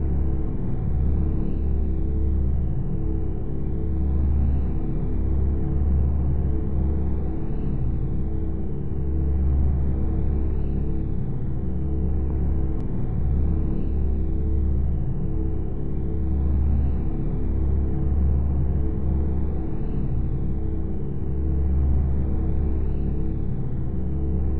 quantimeengine.wav